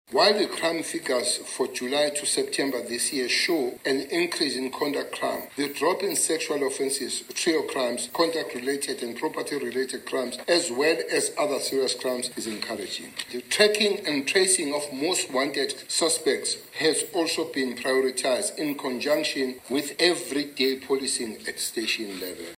# Die minister van Polisie, Bheki Cele, skryf die afname in moorde toe aan die polisie se voortgesette pogings deur beslissende optrede en ‘n sterk gemeenskapsbetrokkenheid. Hy het op ‘n nuuskonferensie gepraat oor die jongste misdaadsyfers.